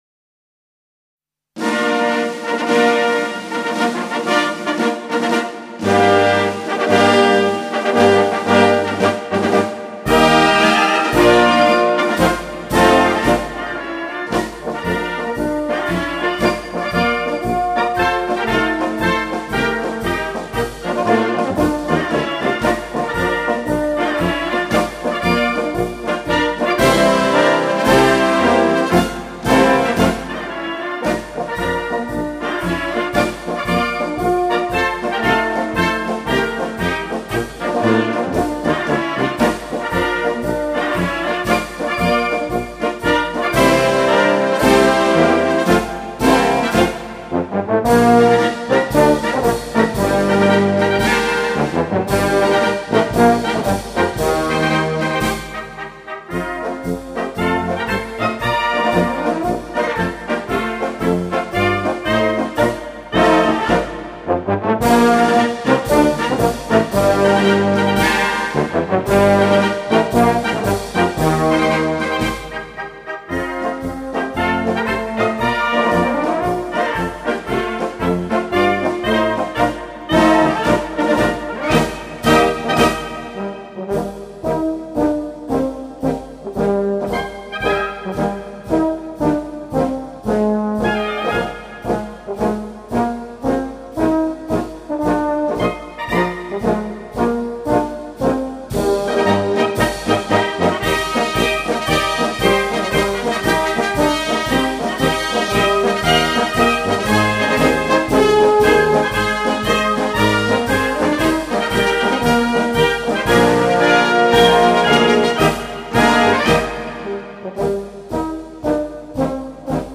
Gattung: Marsch
Besetzung: Blasorchester
Festlicher Marsch für Konzert und Straße